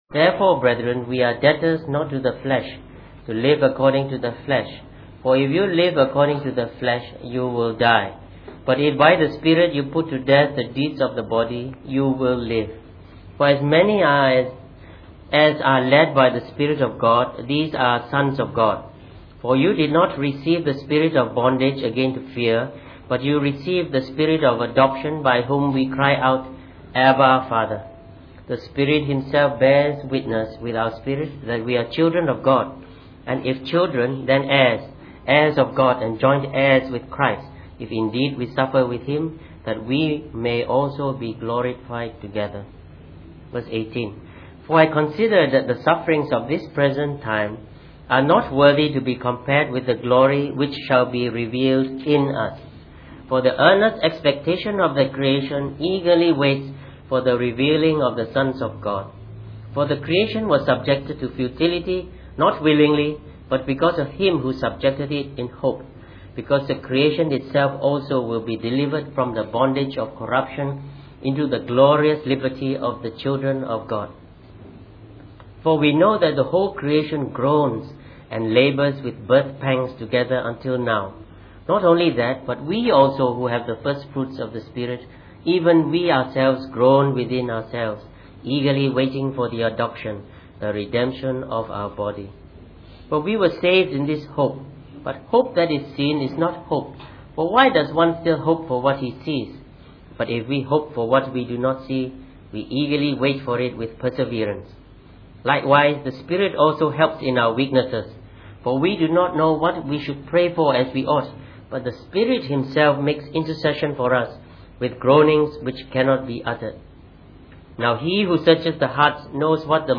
Preached on the 27th of January 2013.